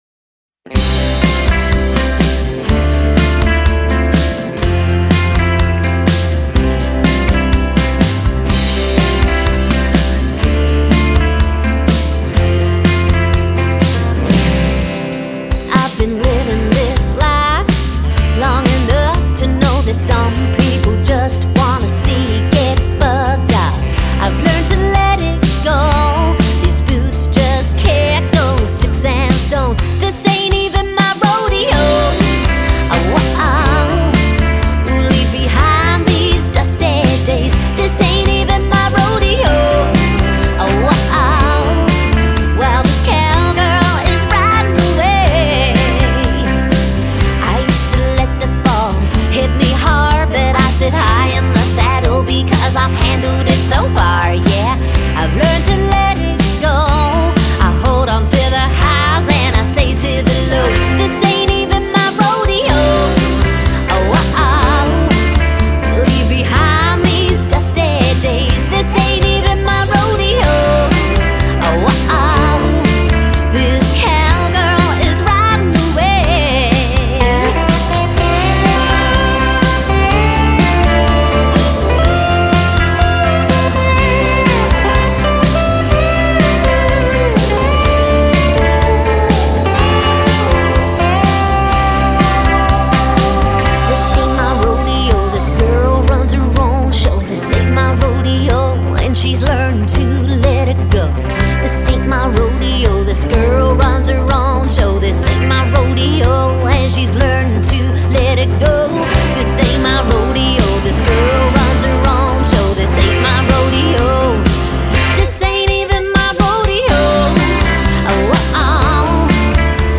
Single Release
” is an anthem of empowerment.